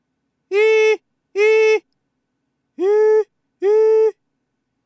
顎を落とし喉頭を下げて発音する
音量注意！
最初の発声は『イ』をなるべく『イ』のまま発音した発声です、後半の2回が顎を落とし喉頭を下げた『イ』です。
こうやって聞くと全然違いますね。
high-vowel-sound-of-i-01.mp3